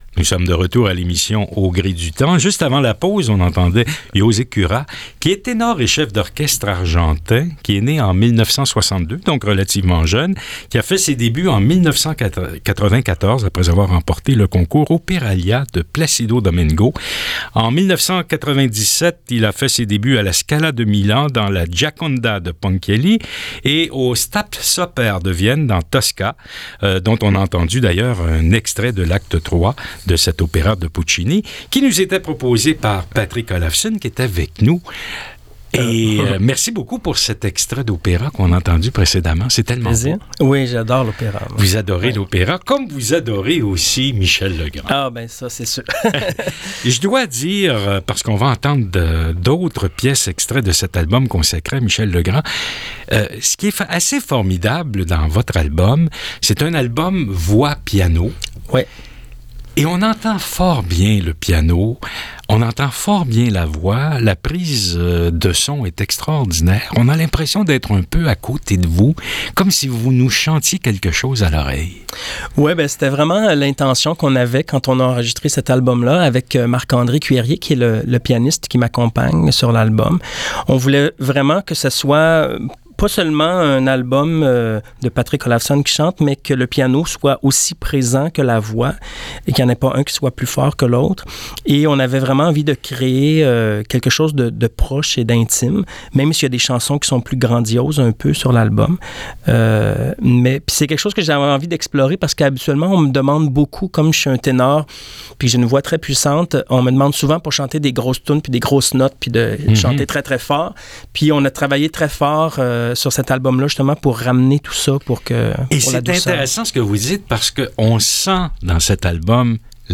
Entrevue radio